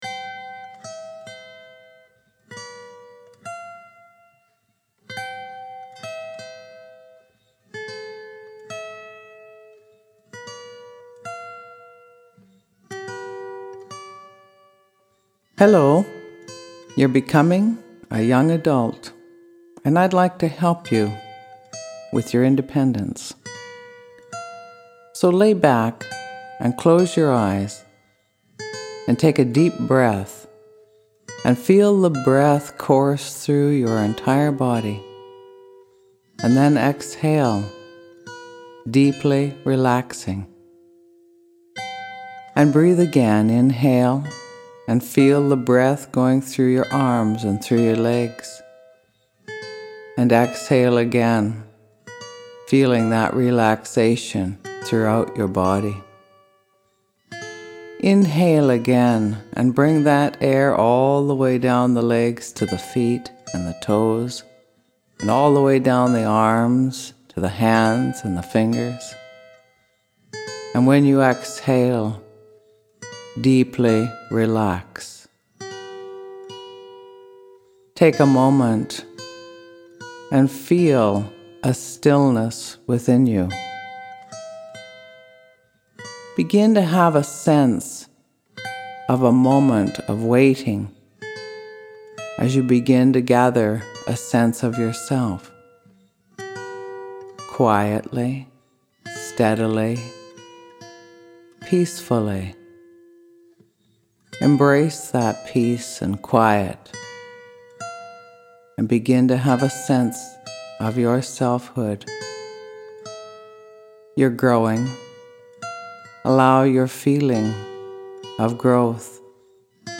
Here is a meditation that honors them, acknowledges their journey and independence, and challenges them to meet their future with confidence. This guided meditation honors our young adults, acknowledges their journey to independence, while inspiring them to envision a future with purpose.